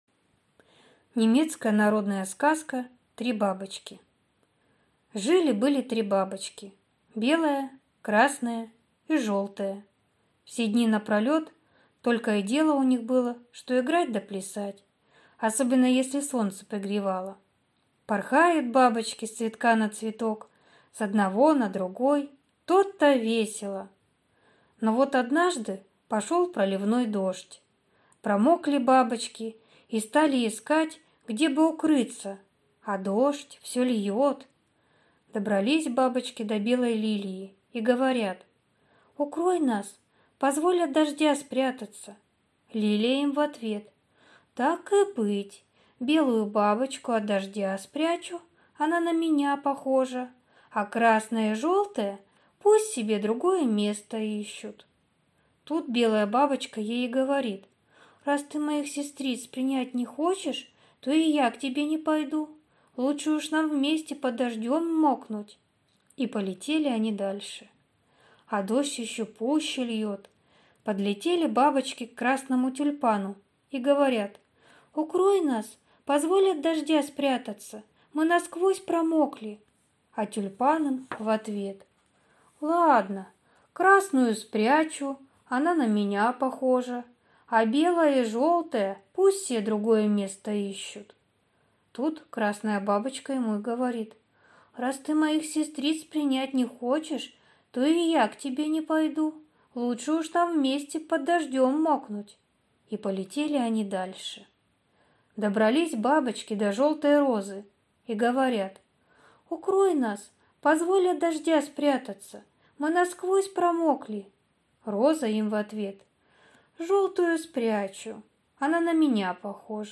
Три бабочки - немецкая аудиосказка - слушать онлайн